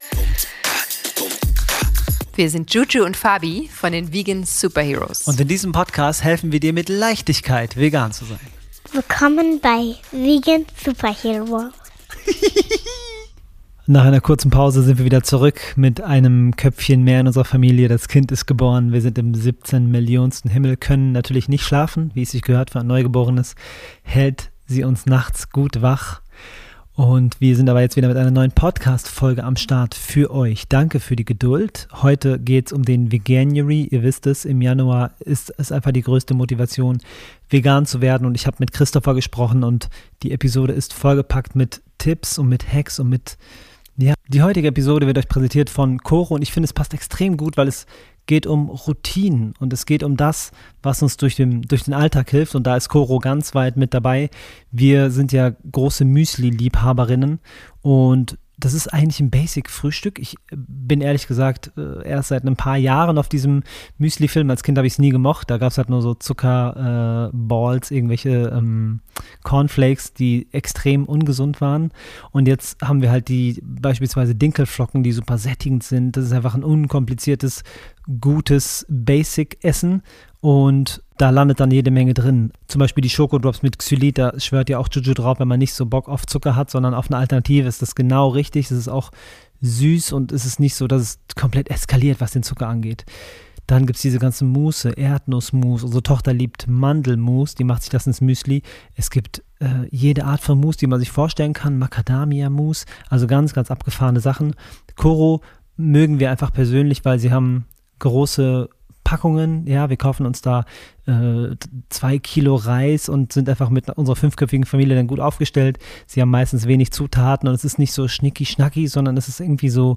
Veganuary Interview - Was hält Menschen 2026 davon ab, vegan zu leben?